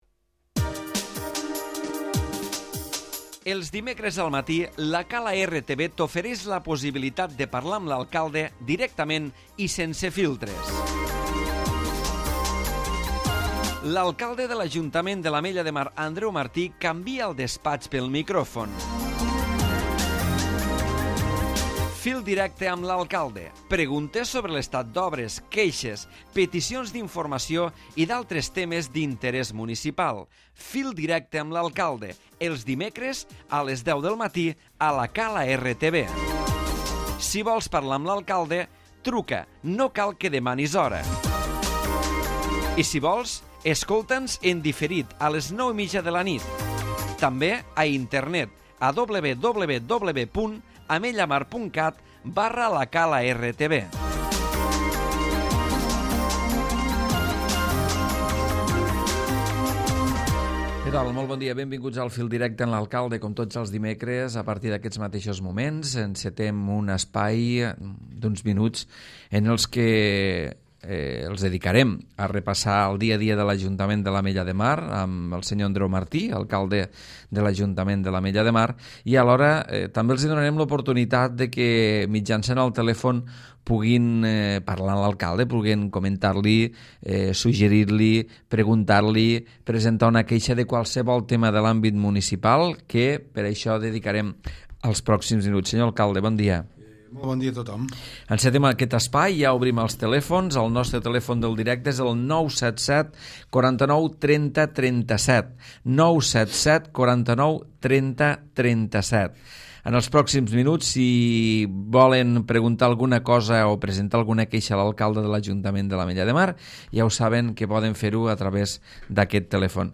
L'Alcalde de l'Ajuntament de l'Ametlla de Mar, Andreu Martí repassa el dia a dia municipal i atésn les trucades dels oients.